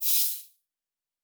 pgs/Assets/Audio/Sci-Fi Sounds/MISC/Air Hiss 2_03.wav at master
Air Hiss 2_03.wav